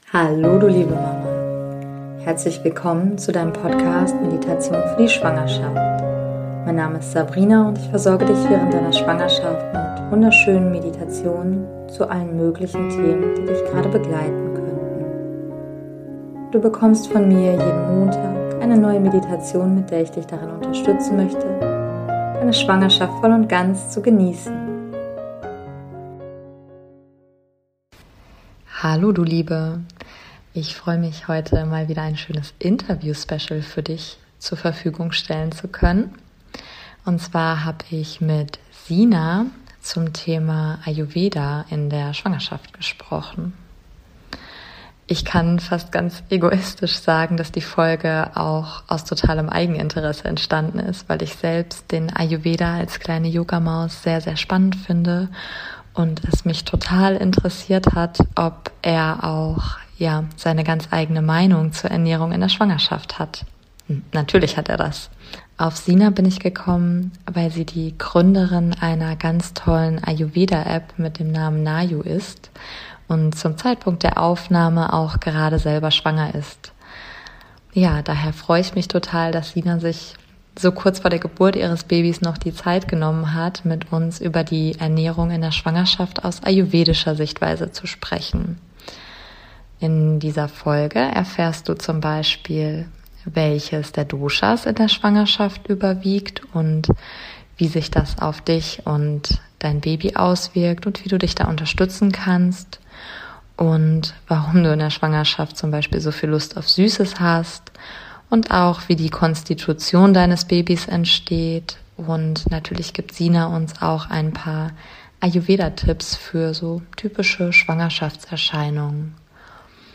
#030 - Ayurveda in der Schwangerschaft - Interview